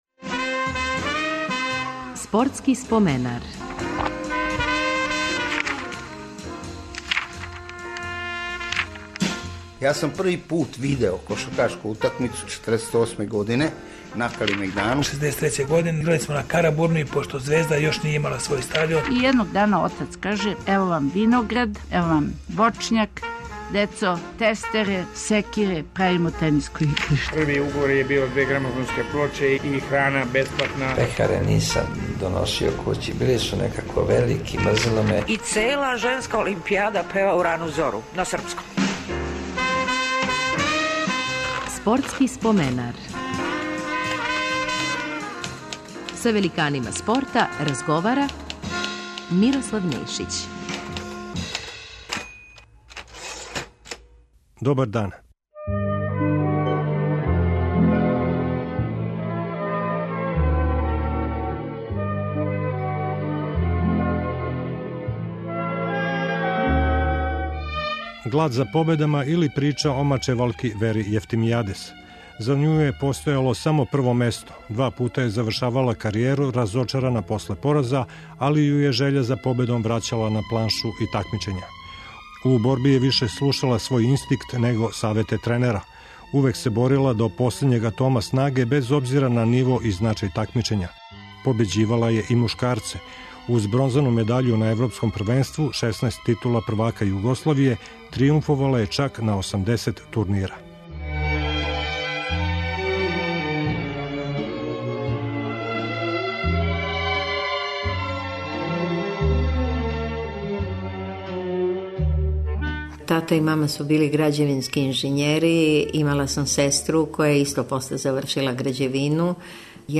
Репризираћемо разговор